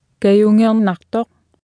Below you can try out the text-to-speech system Martha.
Speech synthesis Martha to computer or mobile phone
Speech Synthesis Martha